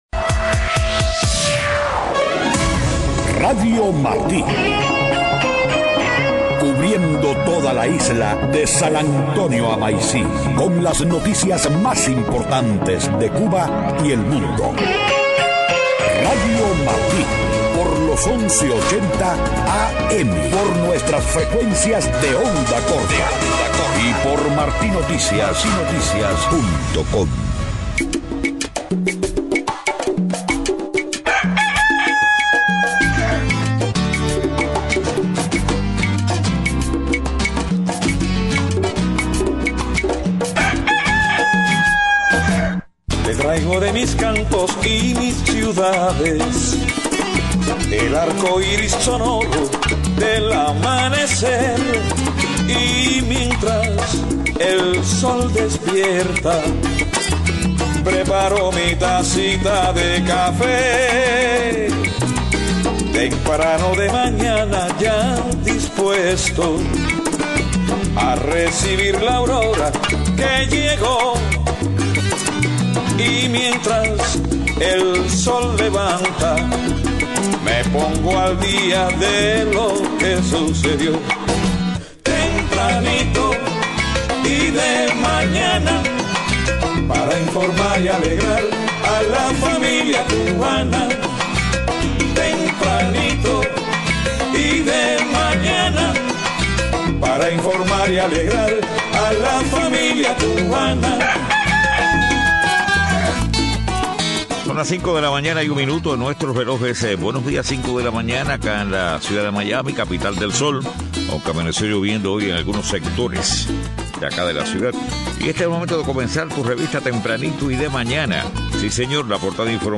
5:00 a.m. Noticias: Movimiento Cristiano Liberación de UE priorizaría los derechos humanos y la libertad en sus conversaciones con Cuba.